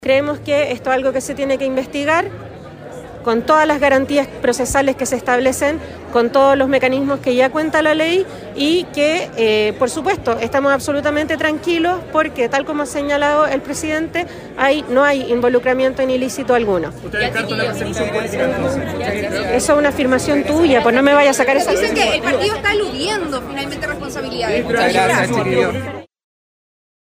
Ayer, en medio de un desayuno de la Cámara Chilena de la Construcción, se intentó conversar con la ministra de La Mujer, Antonia Orellana, rostro del Frente Amplio en el comité político de ministros.
Orellana fue abordada por los periodistas presentes en la actividad.